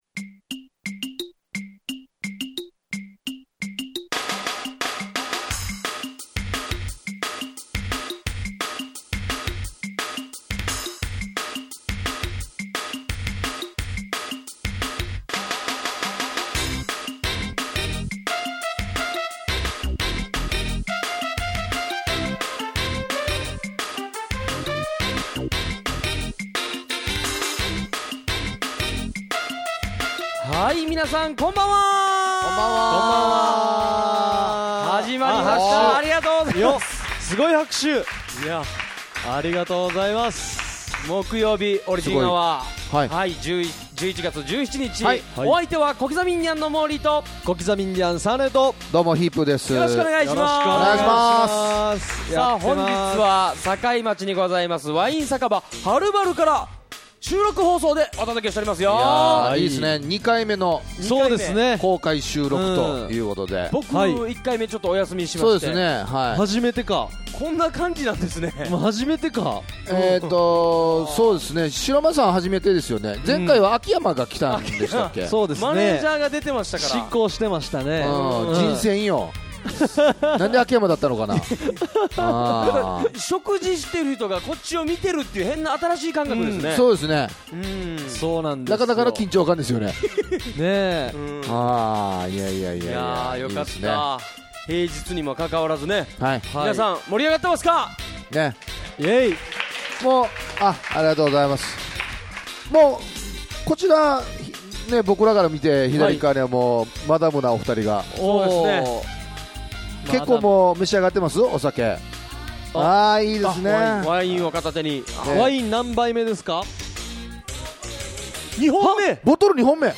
161117こきざみインディアンのオリジンアワー公開収録 in ハル晴ル